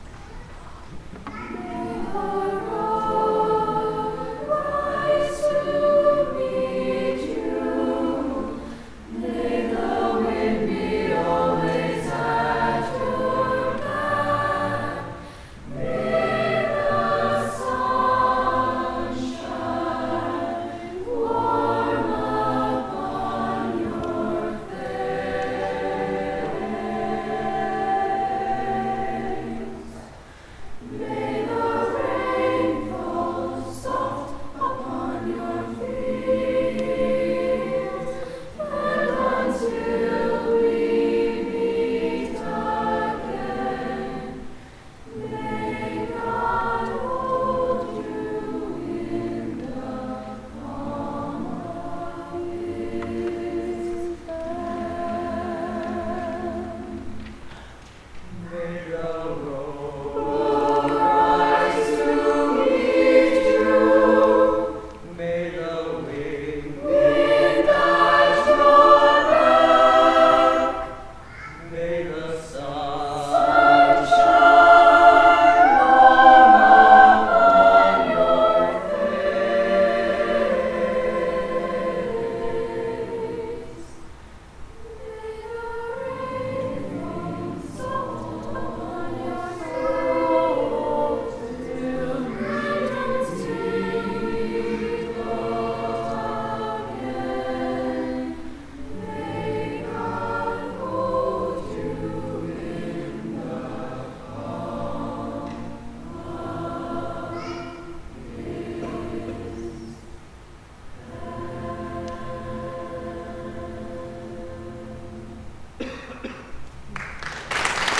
presents the students in a program of Chamber, Choral & Orchestral Music
2:00 PM on July 24, 2016, St. Mary Magdalene